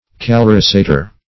Search Result for " calorisator" : The Collaborative International Dictionary of English v.0.48: Calorisator \Ca*lor"i*sa`tor\, n. [NL., heater, fr. L. calor heat.] An apparatus used in beet-sugar factories to heat the juice in order to aid the diffusion.